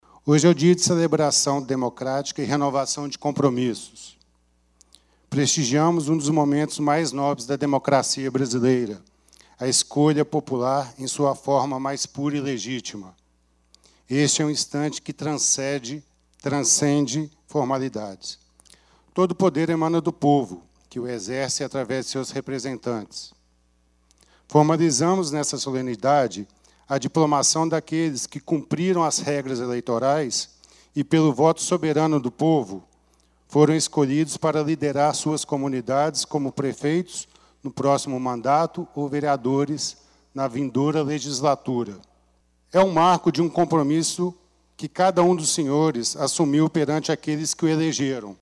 O Promotor eleitoral Dr. Charles Daniel França Salomão também discursou durante a diplomação e lembro que a cerimônia formaliza o desejo do povo através do voto.